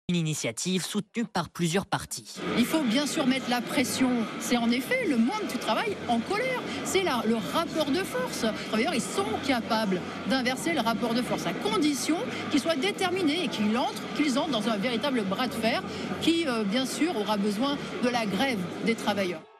M6 dans le journal 19.45 : Reportage sur le meeting parisien